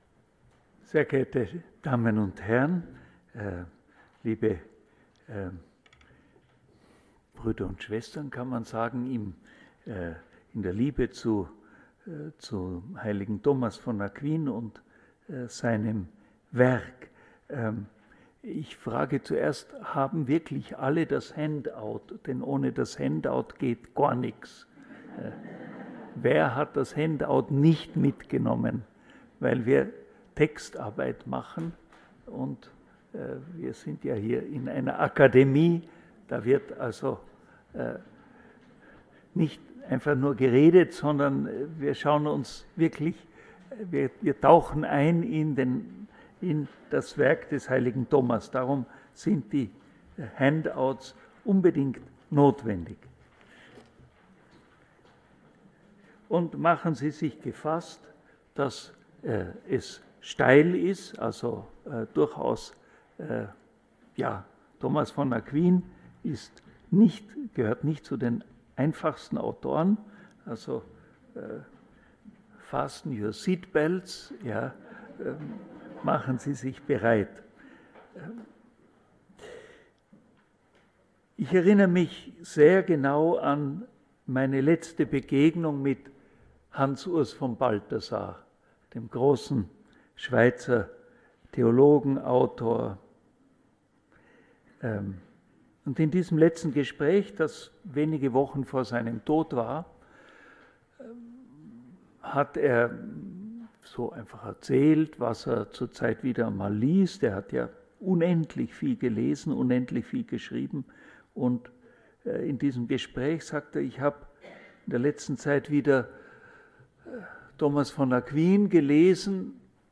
Am 11. März 2019 sprach Erzbischof Dr. Christoph Kardinal Schönborn, Erzdiözese Wien, über seinen Ordensbruder Thomas von Aquin. Sie können seinen Vortrag hier nachhören